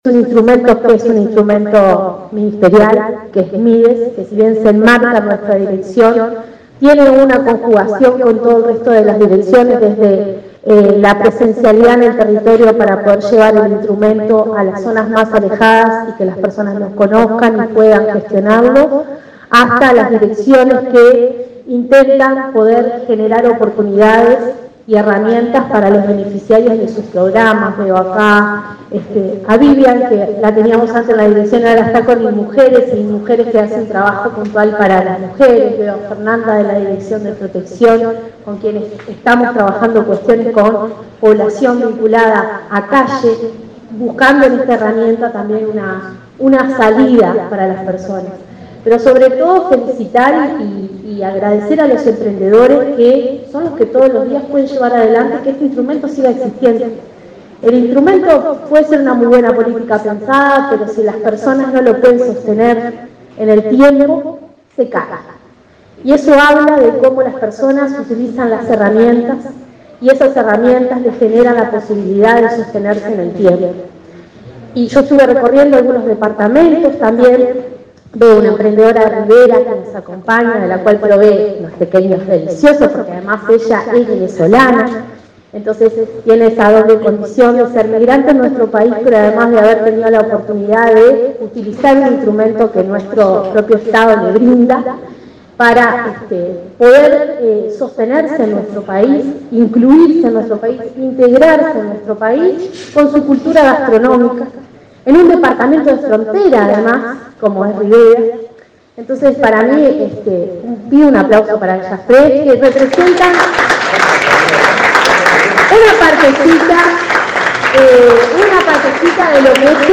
Palabras de autoridades del Mides